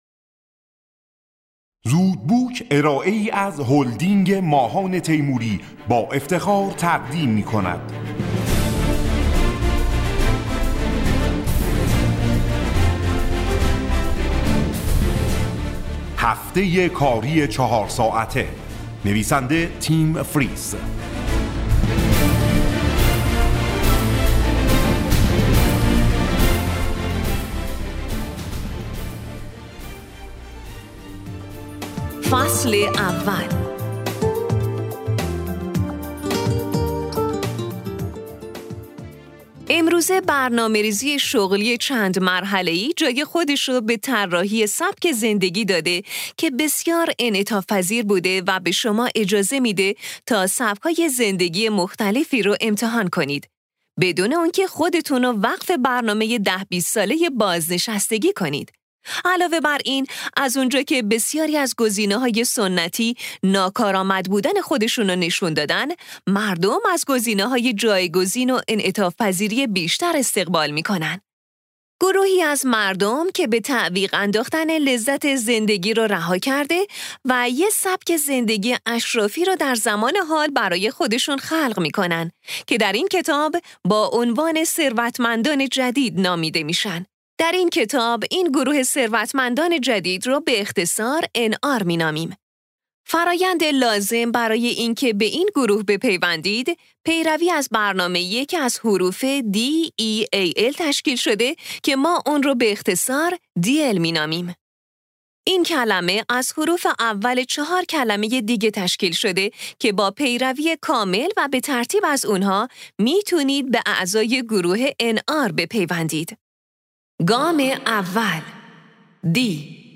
خلاصه کتاب صوتی هفته‌کاری چهار ساعته